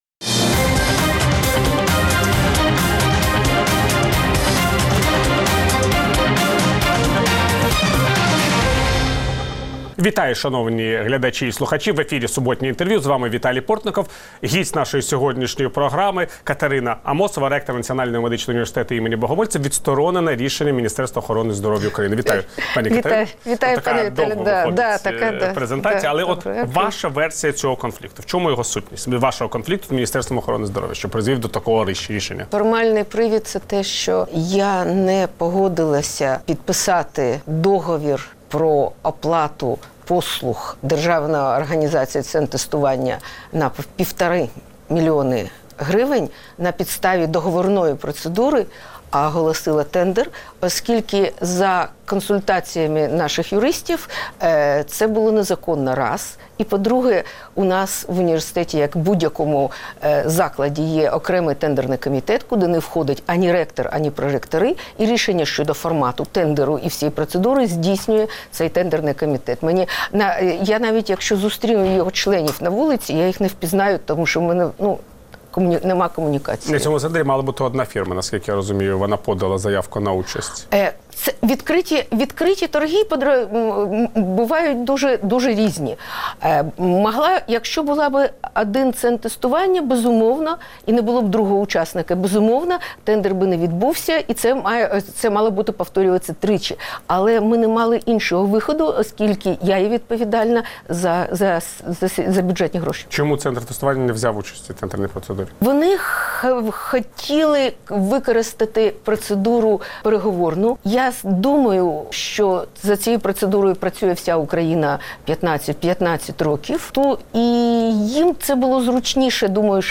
Суботнє інтвер’ю - розмова про актуальні проблеми тижня.